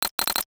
NOTIFICATION_Metal_09_mono.wav